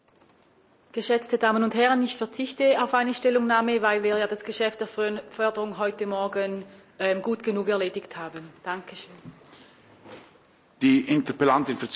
21.9.2021Wortmeldung
Session des Kantonsrates vom 20. bis 22. September 2021